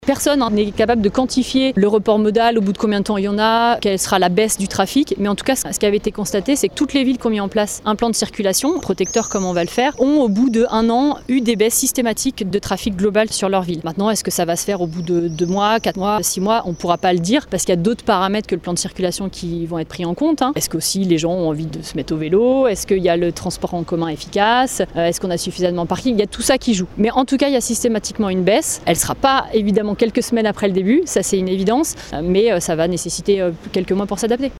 A terme, l’objectif de ce plan de circulation sera bel et bien de faire baisser le trafic comme l’explique Marion Lafarie, elle est adjointe à la ville d’Annecy en charge de la voirie